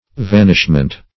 Vanishment \Van"ish*ment\, n.
vanishment.mp3